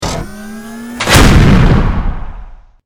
battlesuit_grenade.wav